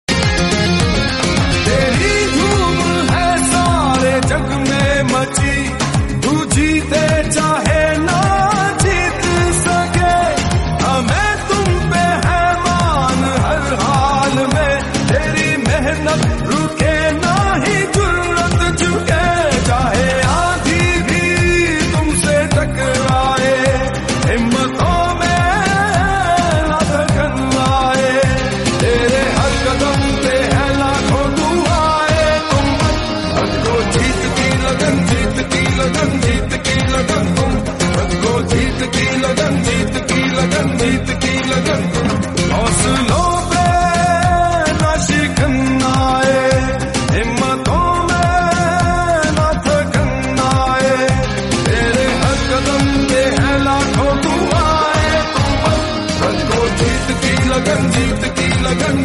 Salman Ali agha Interview after sound effects free download